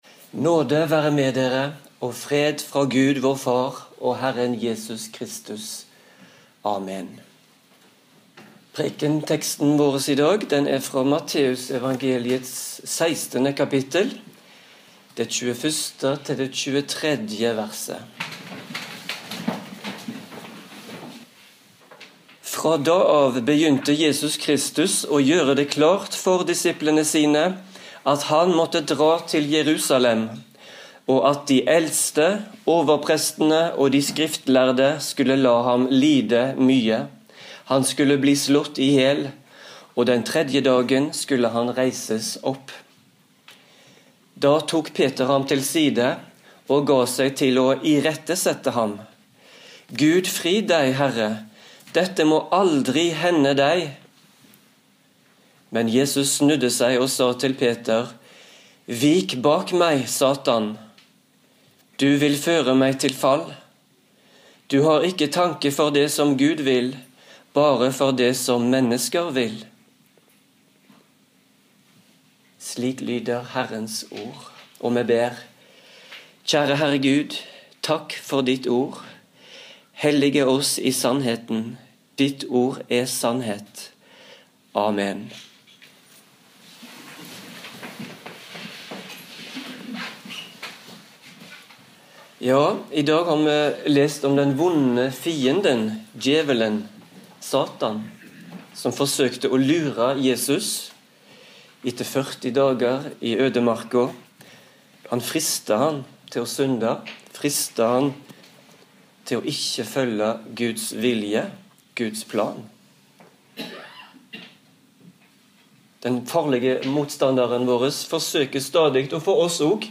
Preken på 1. søndag i faste 2013